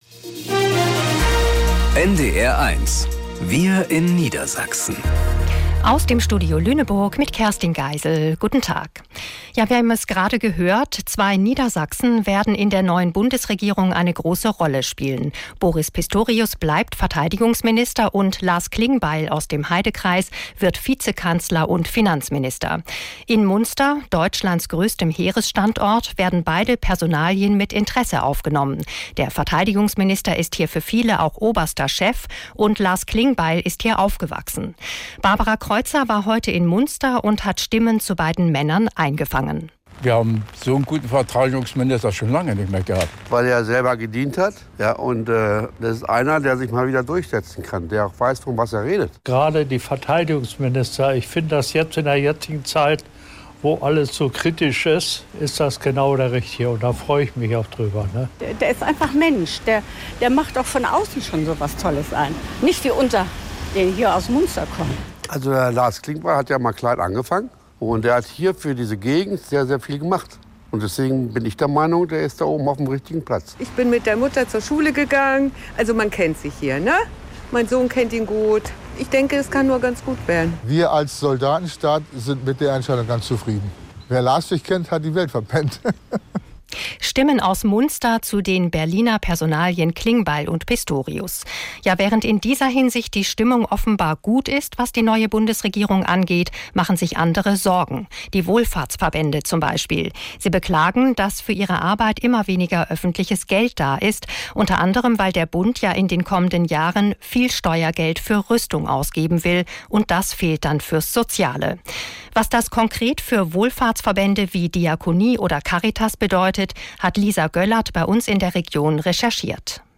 Wir in Niedersachsen - aus dem Studio Lüneburg | Nachrichten